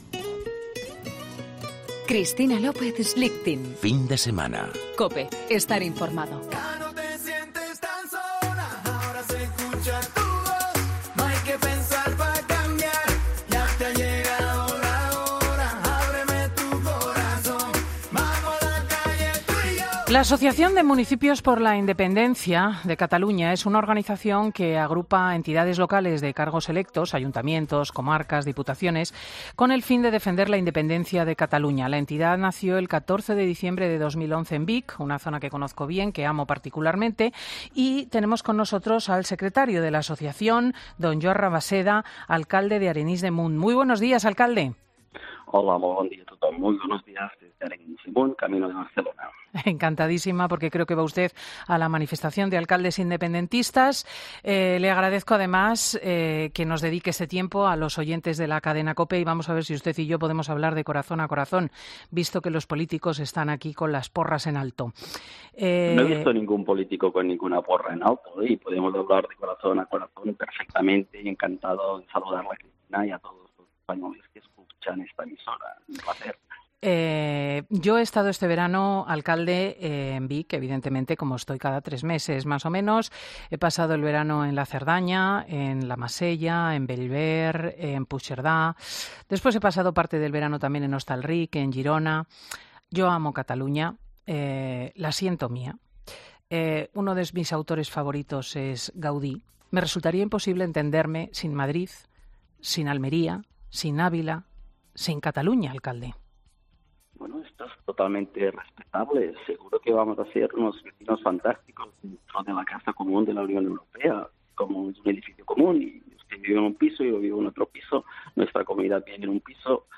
Escucha la entrevista a Joan Rabasseda, alcalde de Areyns de Munt (Barcelona)